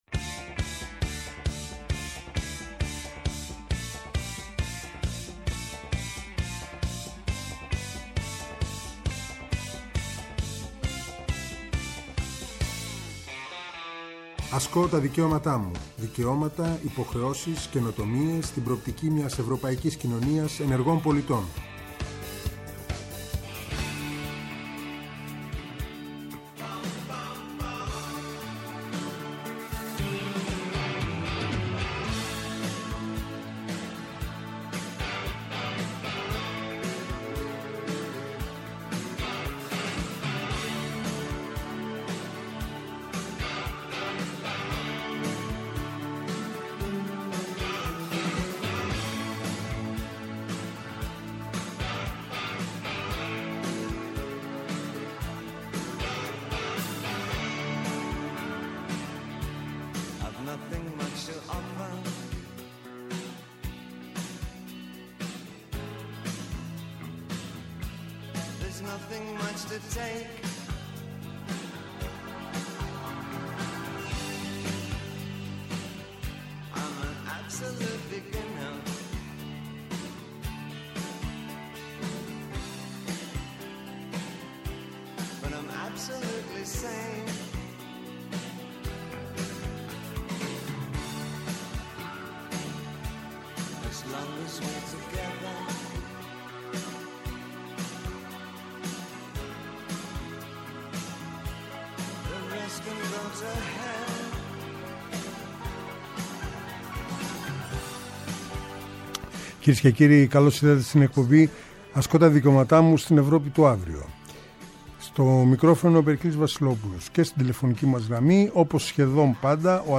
Αυτό το Σάββατο καλεσμένοι :